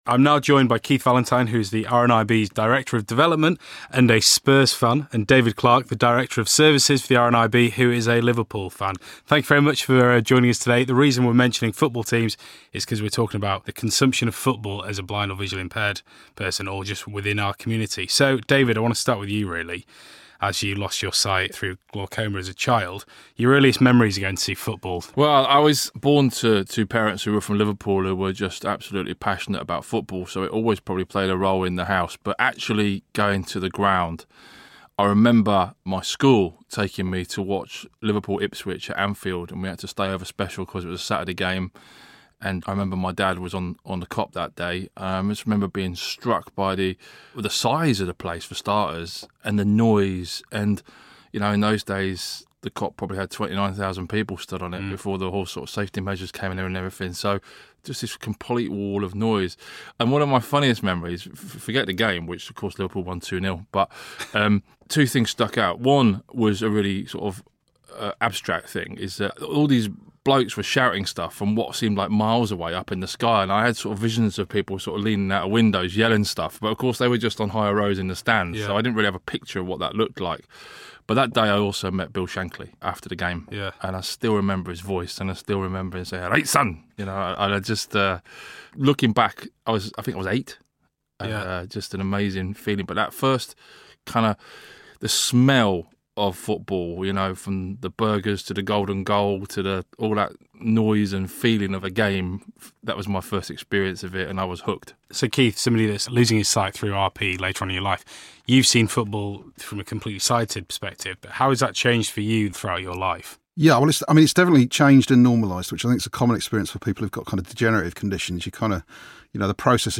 spoke to two of the RNIB’s directors who are both avid football fans for a special in depth look at how they watch football from a blind and partially sighted perspective.